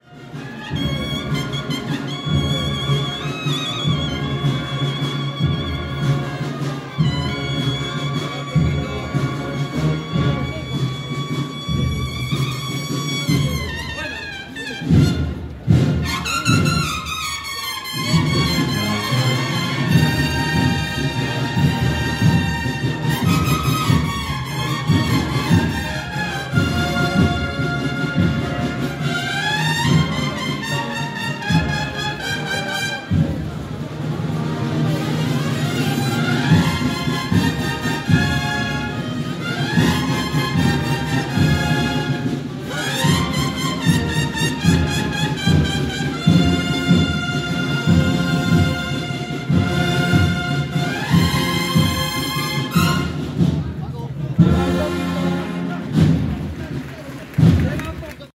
Bandas musicales
Agrupación musical La Unión (Eternidad)